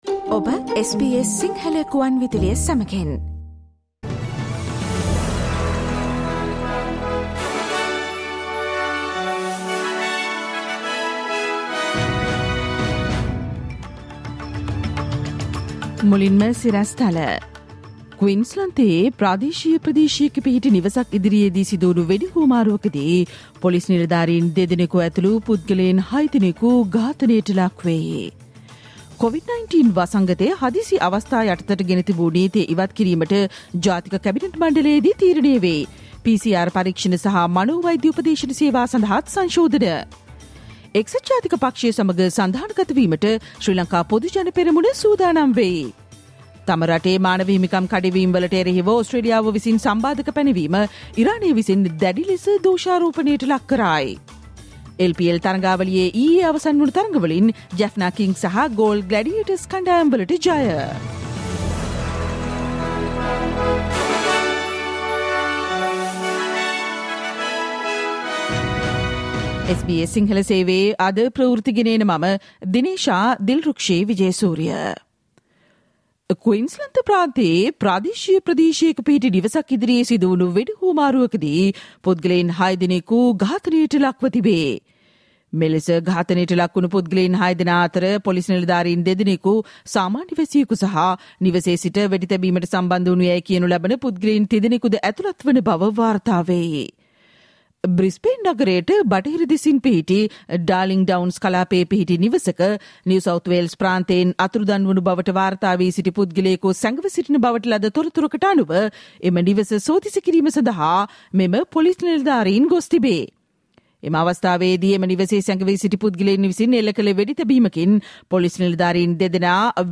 Listen to the SBS Sinhala Radio news bulletin on Tuesday 13 December 2022